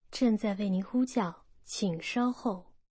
avchat_connecting.mp3